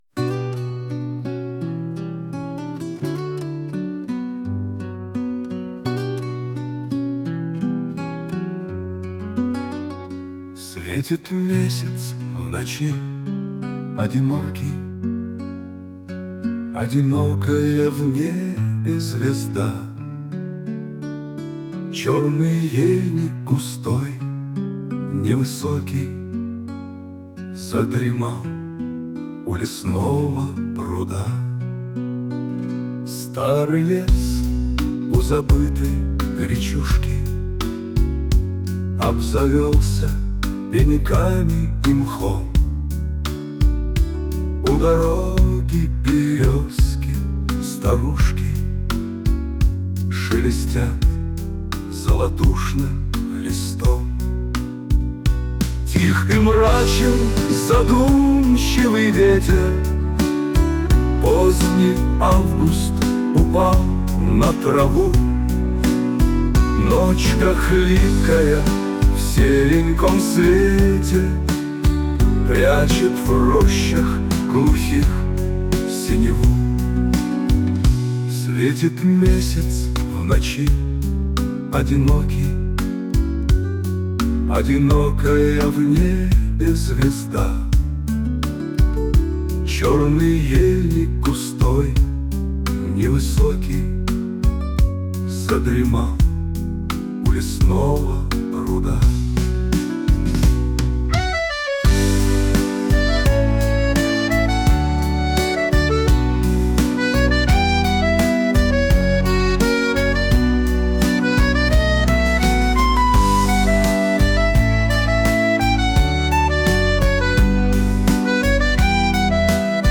песня сгенерирована автором в нейросети: